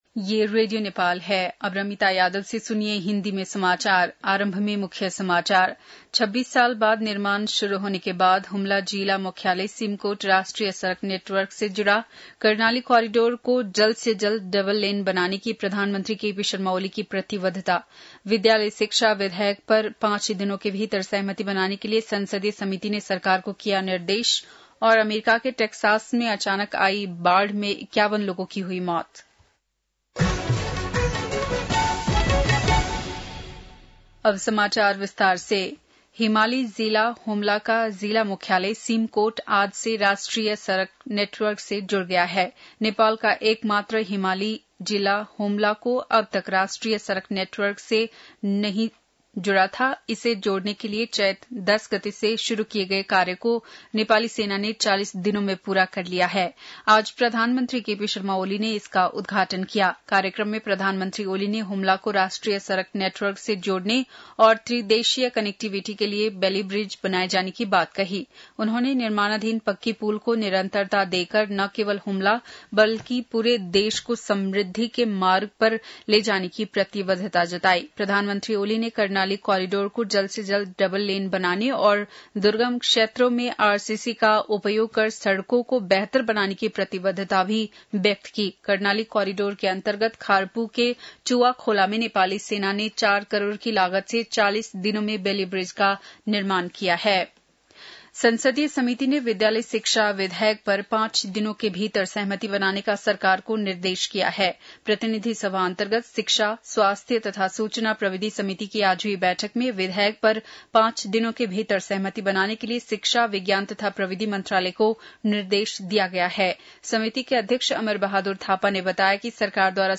बेलुकी १० बजेको हिन्दी समाचार : २२ असार , २०८२
10-pm-hindi-news-.mp3